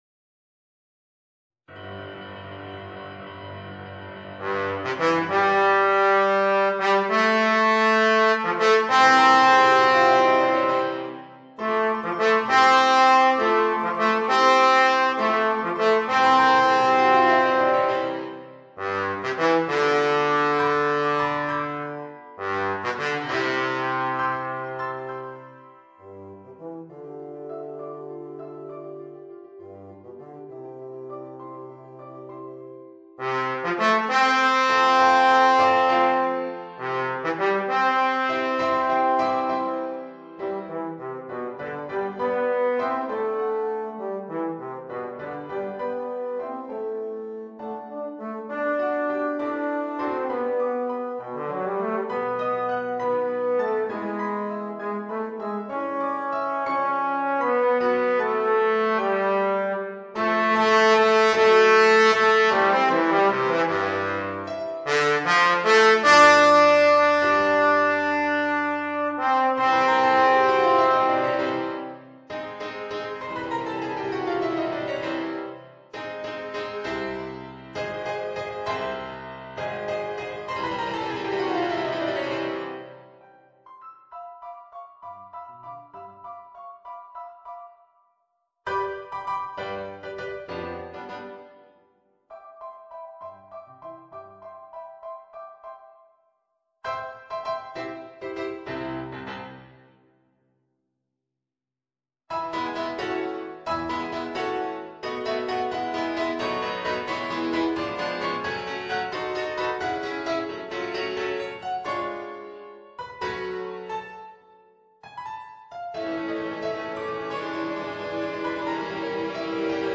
Voicing: Bass Trombone and Piano